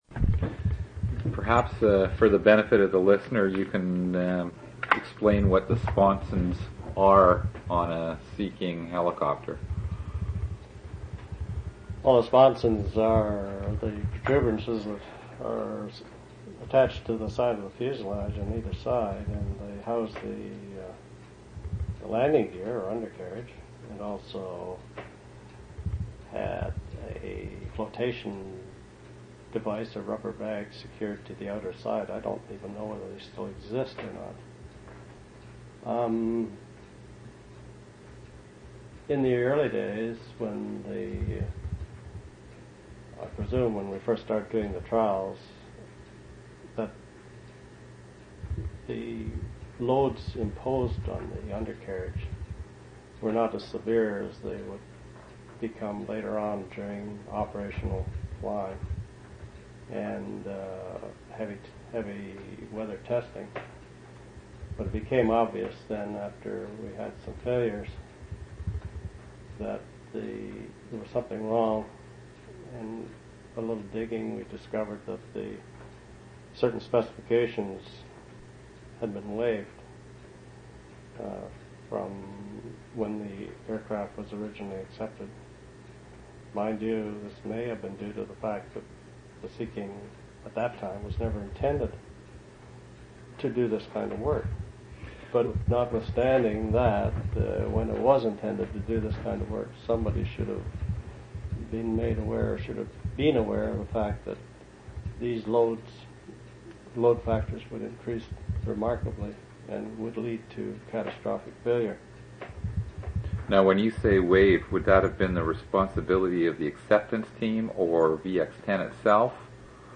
Two original audio cassettes in Special Collections.
oral histories (literary genre) sound recordings interviews reminiscences